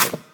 step-2.ogg